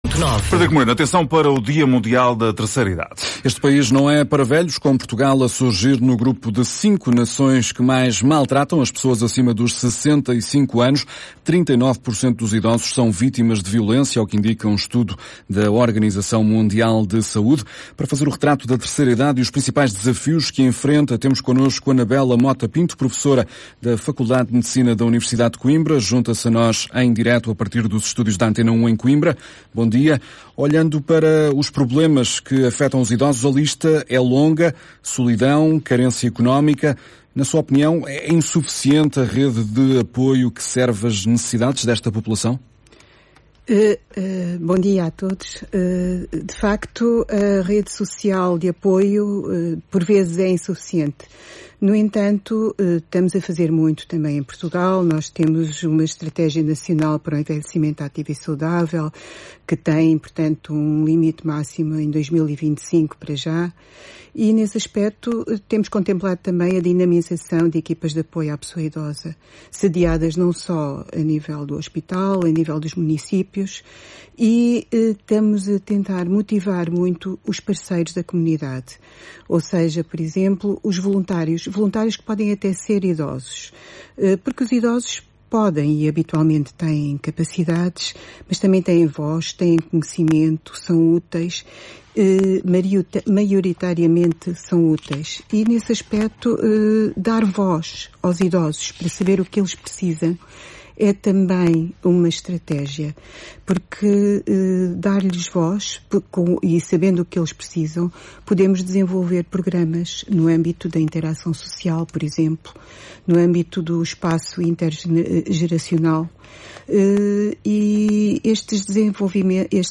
Entrevista a professora da FMUC sobre o Dia Mundial da Terceira Idade